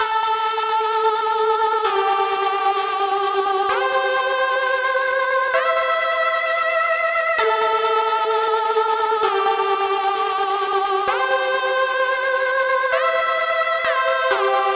合唱团默示录
描述：平滑的陷阱高音调的正能量。欣赏
标签： 130 bpm Hip Hop Loops Choir Loops 2.48 MB wav Key : Unknown
声道立体声